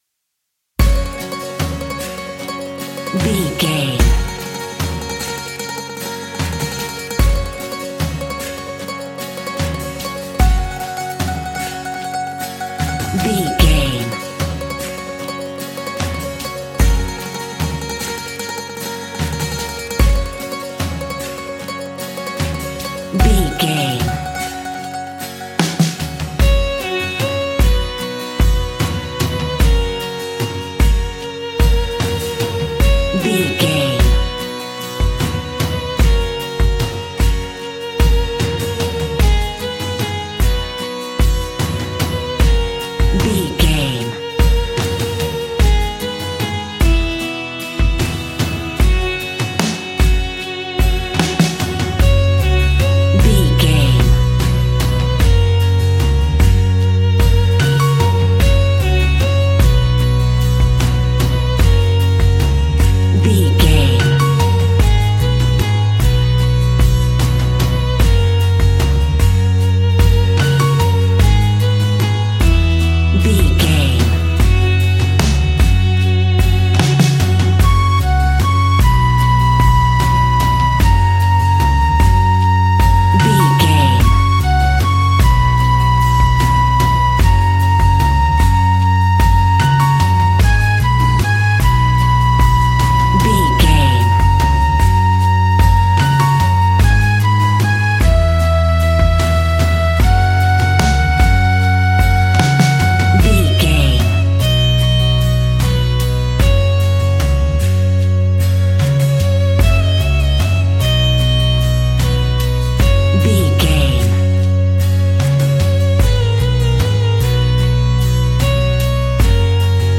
Uplifting
Ionian/Major
G♭
acoustic guitar
mandolin
ukulele
lapsteel
drums
double bass
accordion